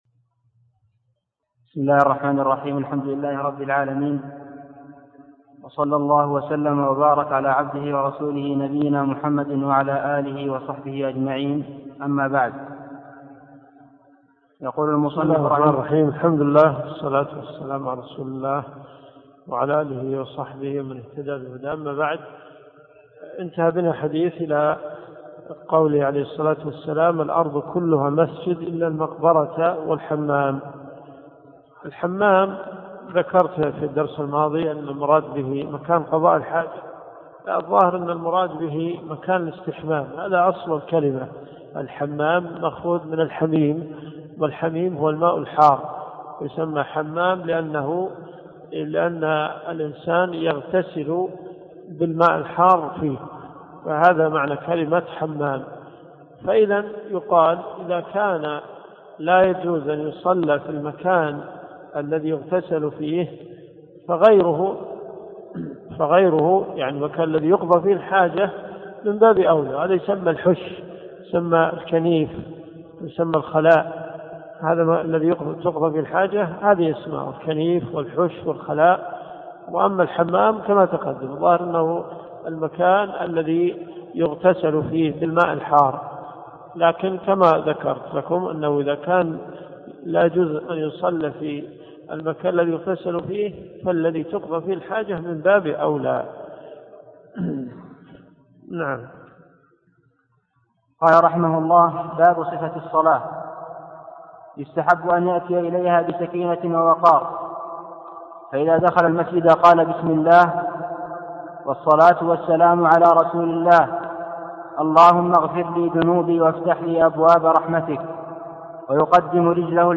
الدروس الشرعية
المدينة المنورة . جامع البلوي